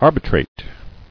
[ar·bi·trate]